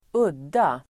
Uttal: [²'ud:a]